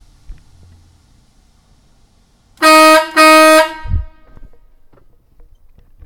air horn close and loud
close loud truckair-horn sound effect free sound royalty free Memes